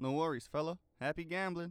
Added all voice lines in folders into the game folder